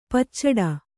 ♪ paccaḍa